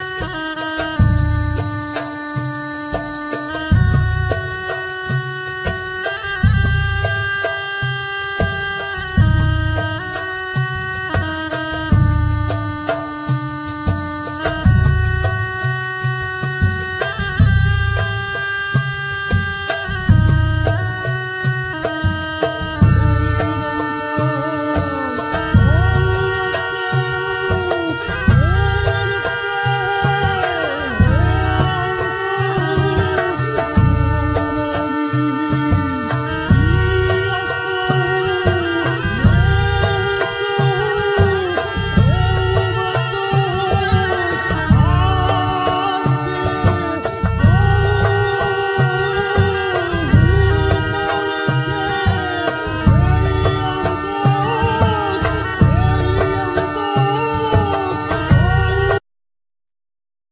Guitar,Clarinetto,Oboe,Flute,Tamburo,Vocal
Tres cumano,Tammorra a sonagli,Chorus
Percussions,Sonagli,Timpani,Chorus
Darbouka,Chorus
Violin,Chorus